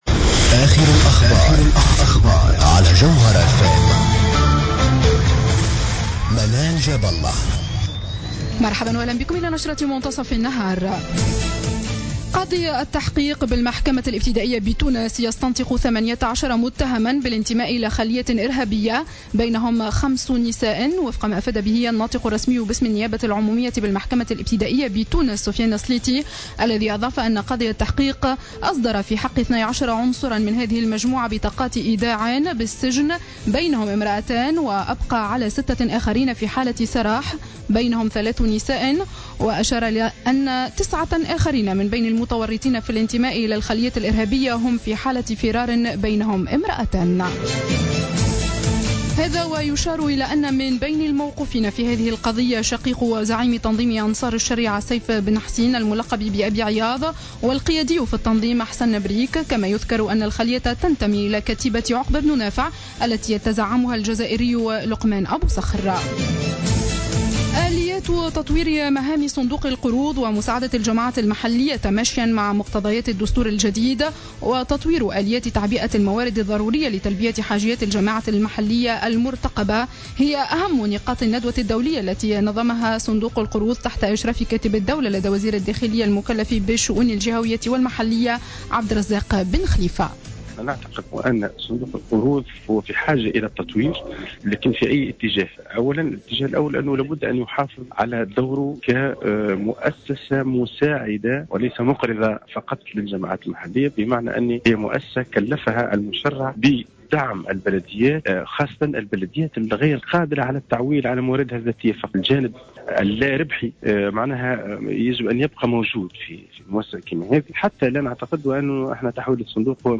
نشرة أخبار منتصف النهار ليوم الثلاثاء 14-10-14